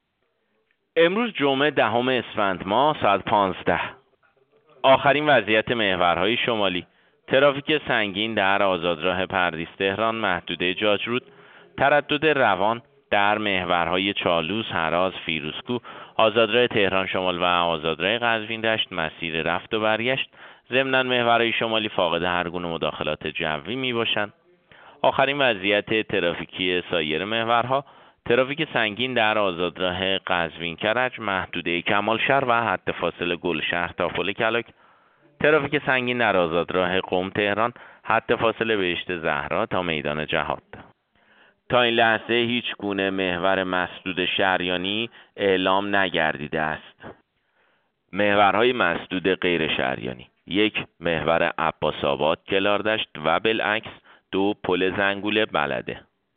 گزارش رادیو اینترنتی از آخرین وضعیت ترافیکی جاده‌ها ساعت ۱۵ دهم اسفند؛